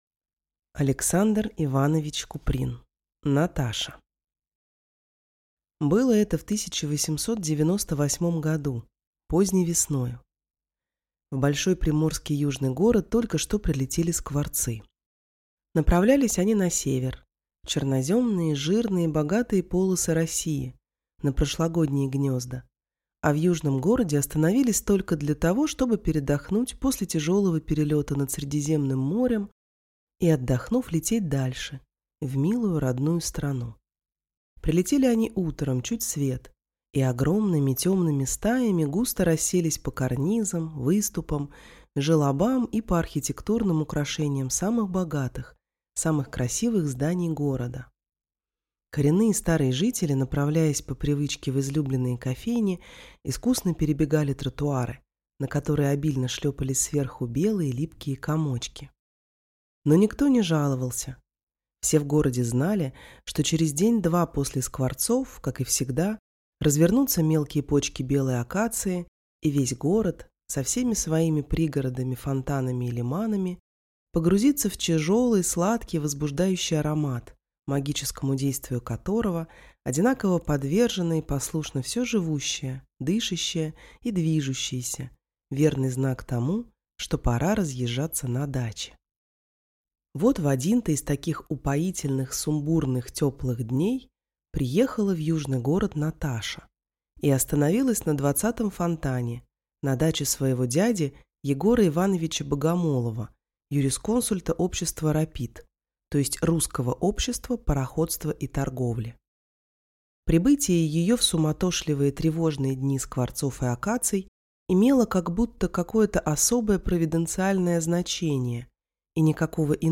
Aудиокнига Наташа